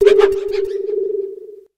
Grito de Rabsca.ogg
Grito_de_Rabsca.ogg